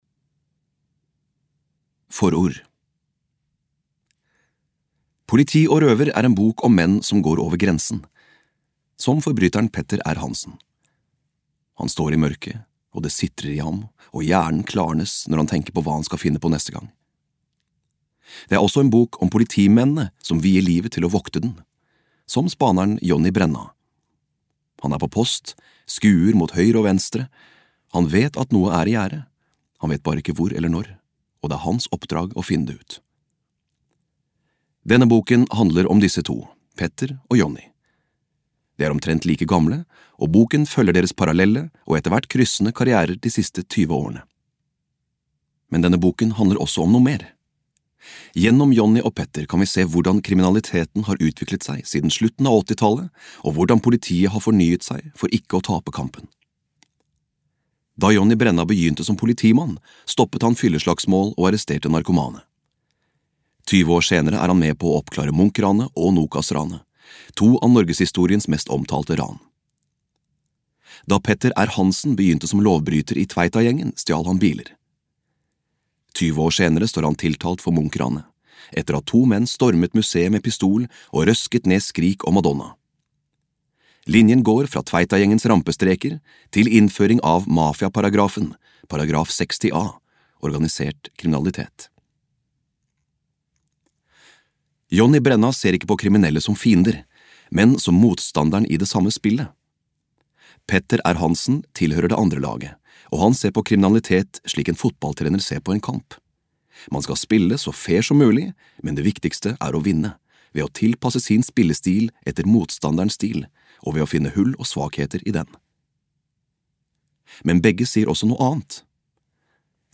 Politi & røver (lydbok) av Kjetil Stensvik Østli